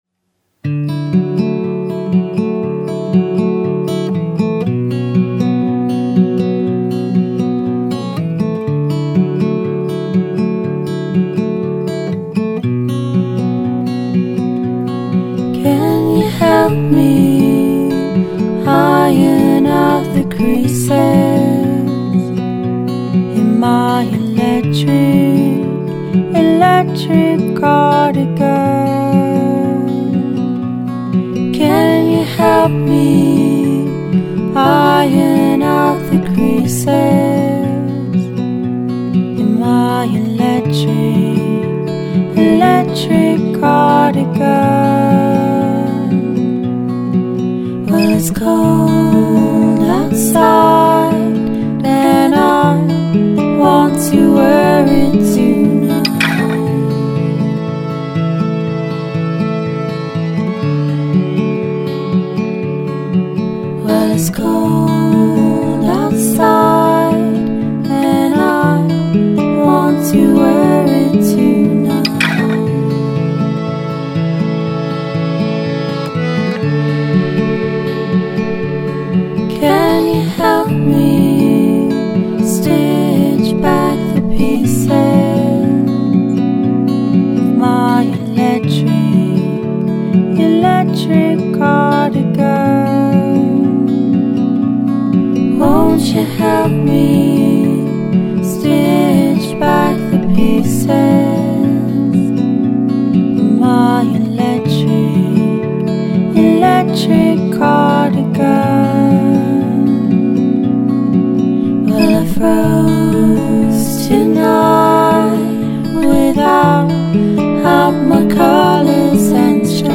pieno di piccoli incanti folk